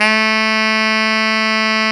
Index of /90_sSampleCDs/Roland LCDP07 Super Sax/SAX_Tenor mf&ff/SAX_Tenor ff
SAX TENORF0B.wav